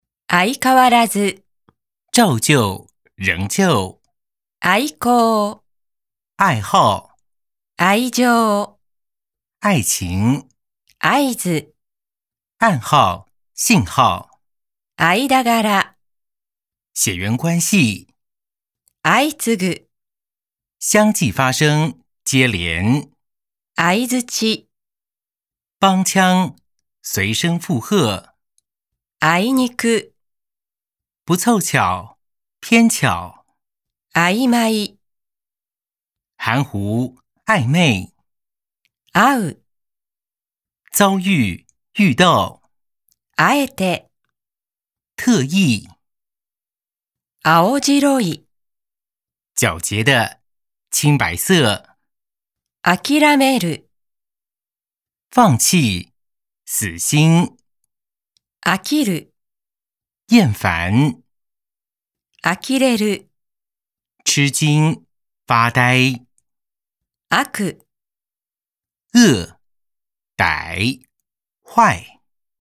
用聽的背日檢N2單字3700 （長20K+日中朗讀MP3）
MP3大力播放，一日一中：一個日文單字配上一個最重要的中文意思，利用下MP3利器，像被洗腦一般，每個日文單字在腦海中揮之不去！